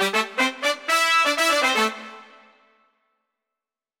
31 Brass Last Bar+Tail.wav